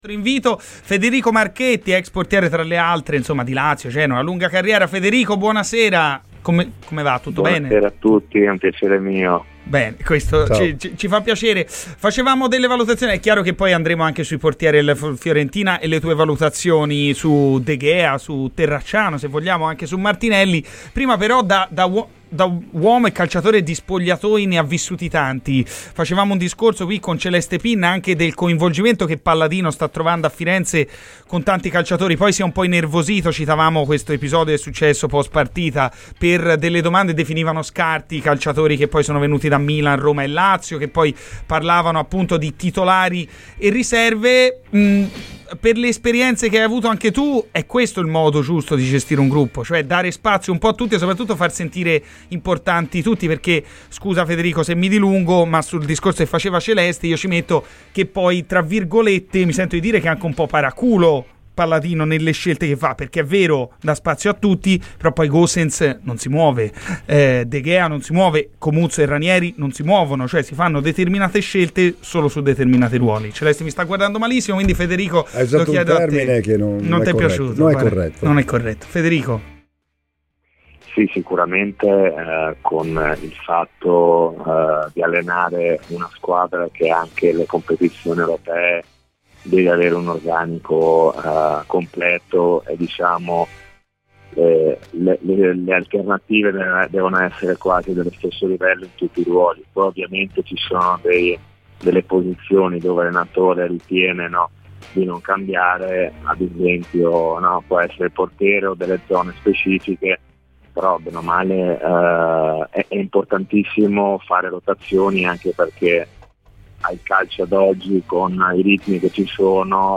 Ascolta il podcast per l'intervista intera!